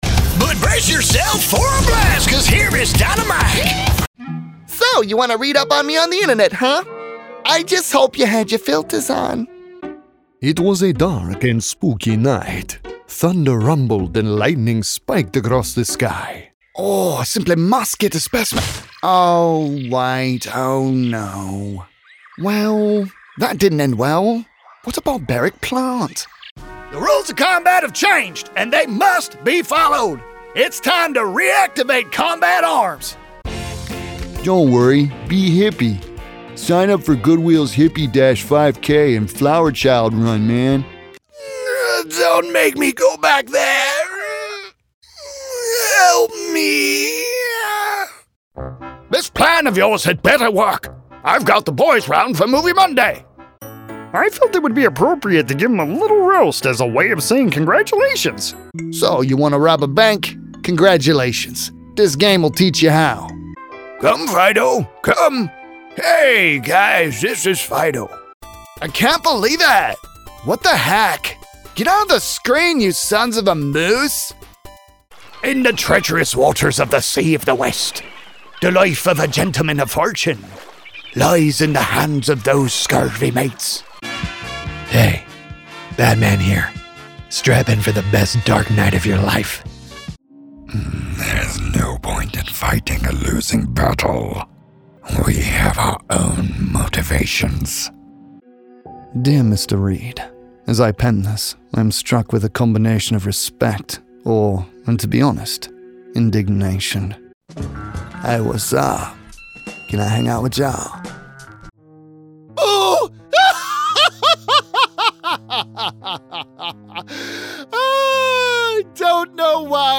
Male
English (North American)
Adult (30-50)
Video Games
Words that describe my voice are guy next door, trustworthy, warm.